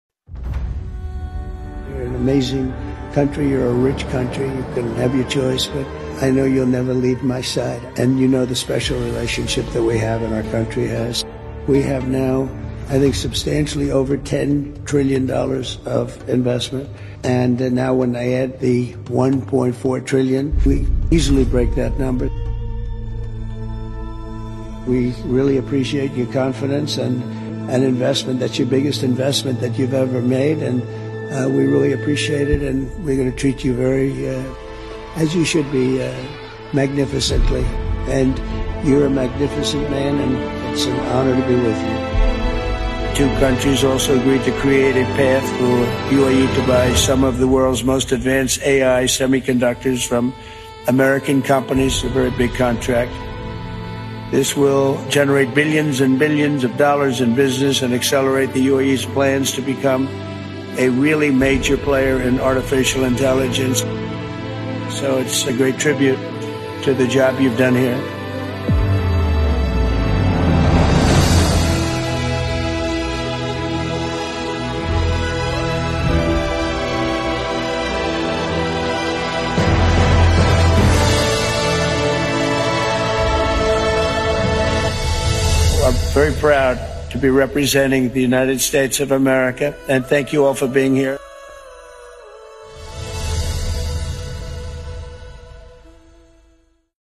President Donald J. Trump Visits the UAE
president-donald-j-trump-visits-the-uae.mp3